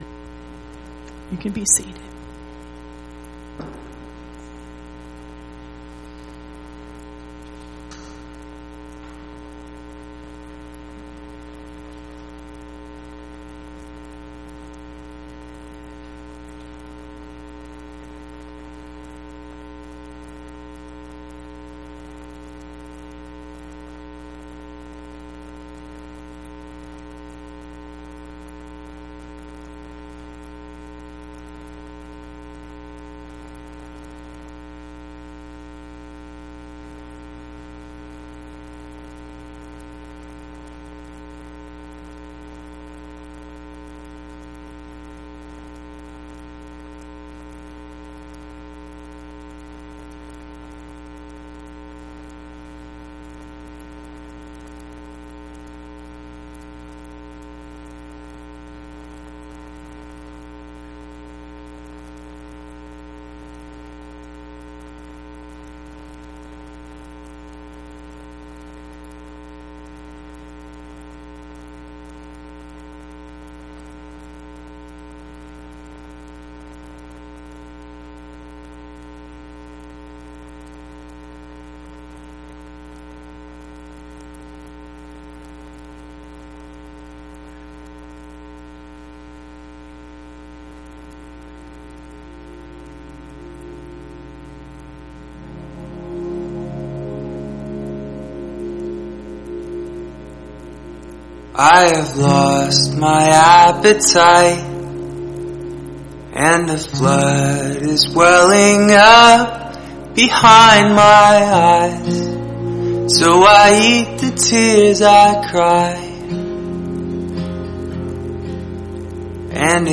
Sermon-5-20-18.mp3